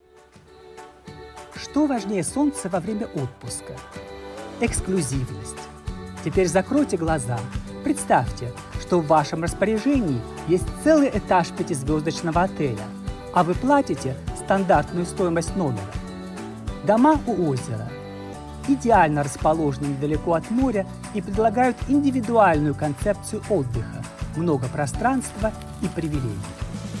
Russian Hotel Commercial Showreel
Male
Bright
Down To Earth
Friendly